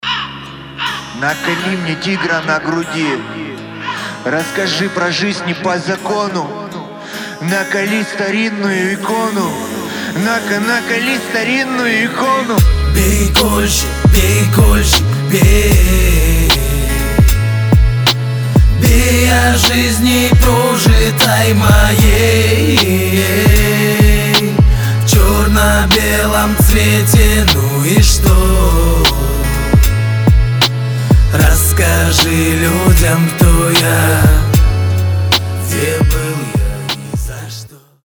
• Качество: 320, Stereo
душевные
грустные
блатные
тюремная лирика